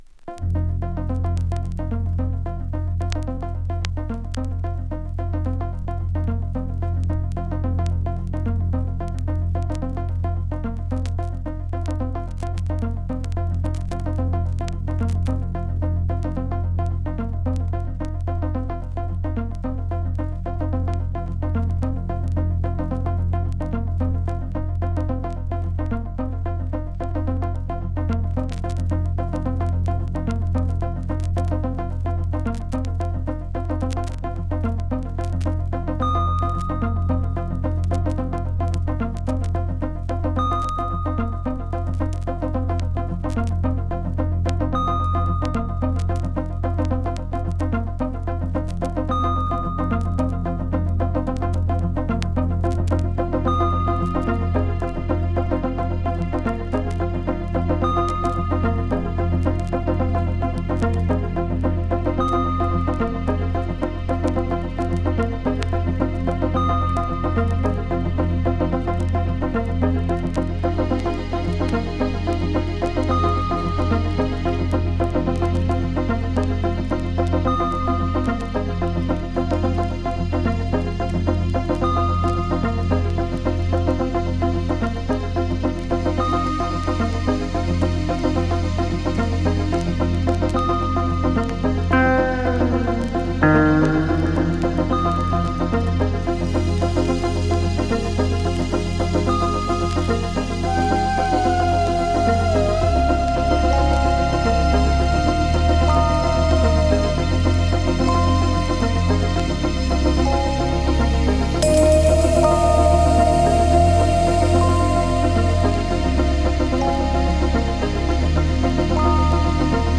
2. > TECHNO/HOUSE